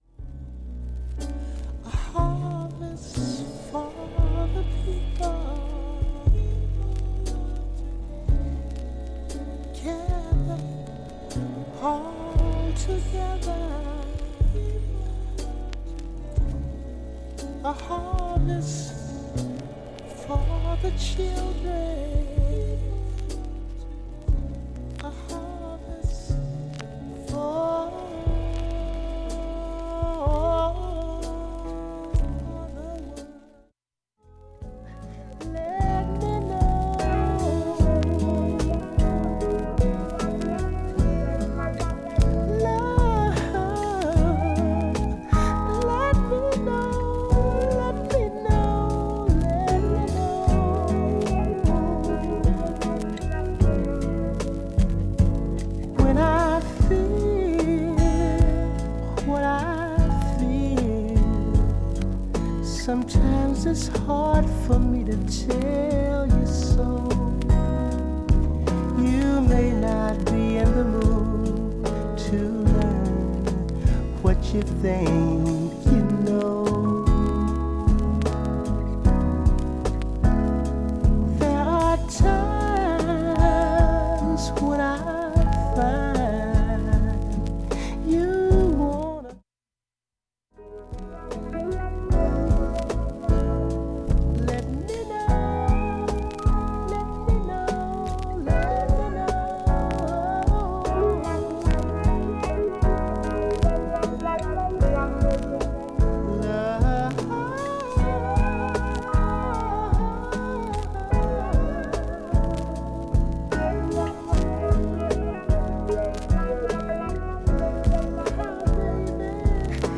音質良好全曲試聴済み。
６回までのかすかなプツが１箇所
３回までのかすかなプツが６箇所
単発のかすかなプツが７箇所